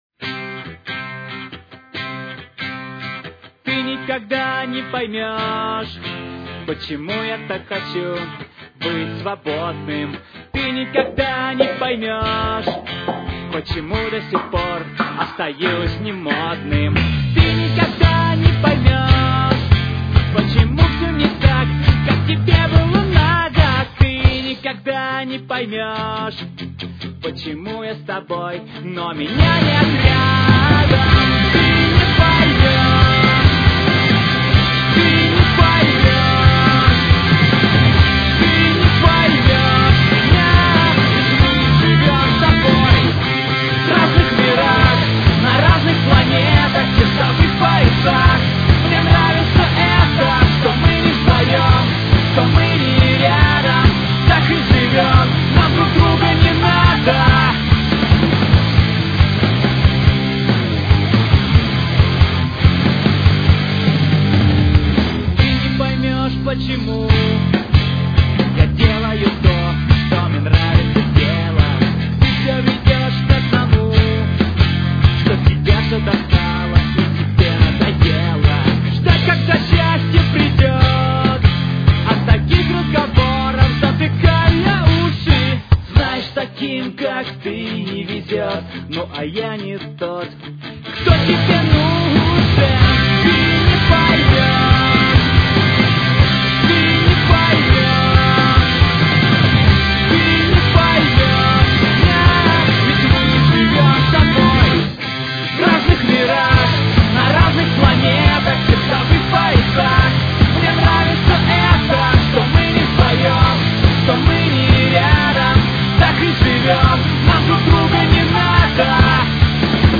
жанр поп панк!